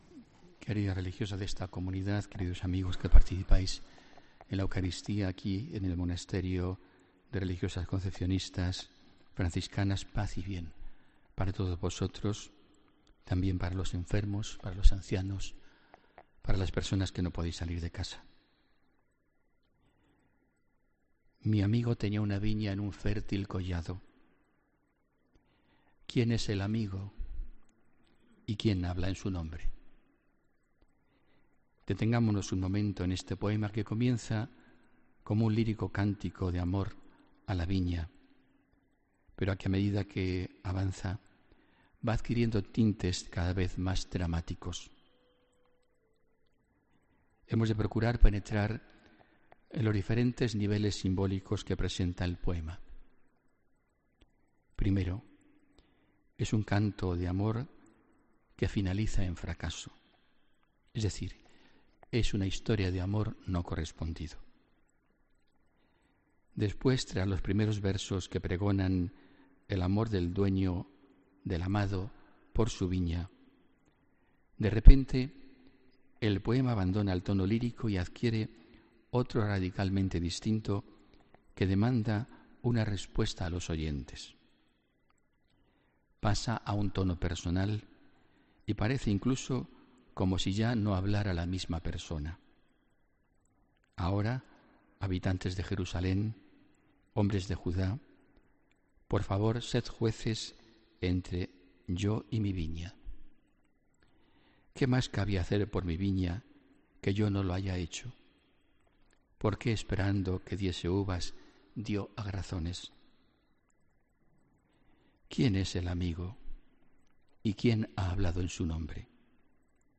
Homilía del domingo 8 de octubre de 2017